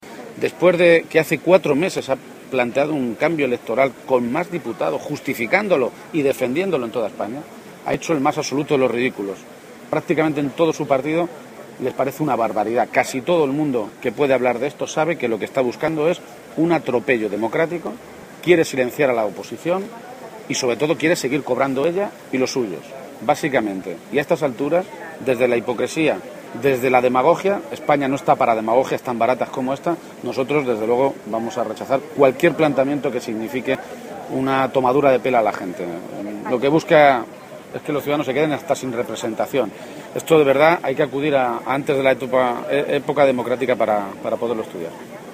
García-Page se ha pronunciado así en Puertollano, localidad a la que ha acudido para participar en sus fiestas patronales, un escenario en el que ha criticado el afán de Cospedal por querer acabar con la política o desprestigiarla, quizá, ha sugerido, porque “probablemente en toda España va a costar encontrar a alguien con menos capacidad de ejemplo de cómo se debe hacer política como la señora Cospedal”.